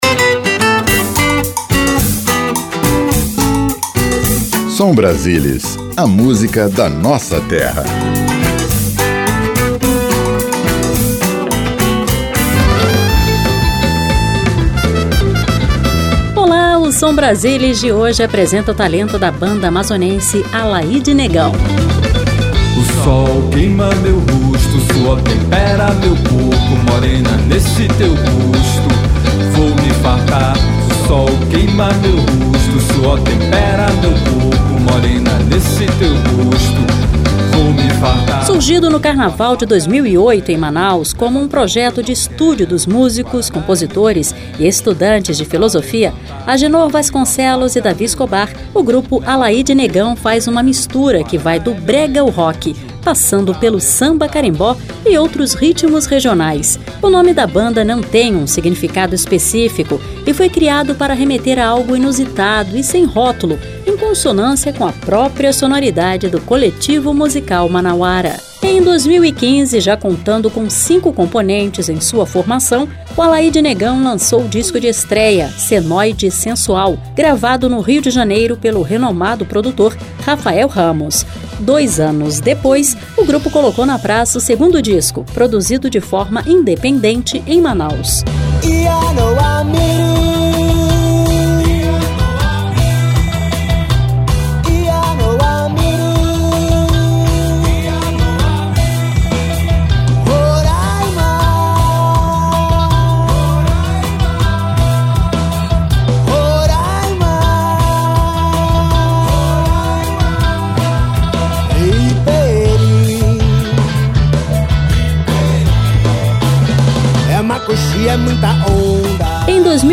Samba
Ao vivo